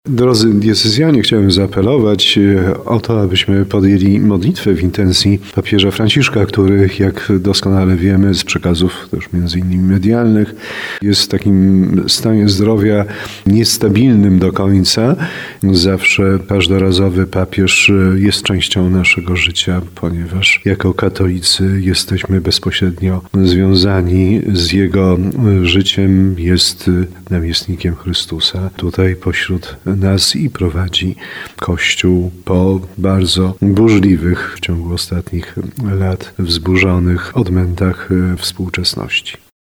Apel do diecezjan za pośrednictwem Radia RDN Małopolska i RDN Nowy Sącz skierował także biskup tarnowski Andrzej Jeż.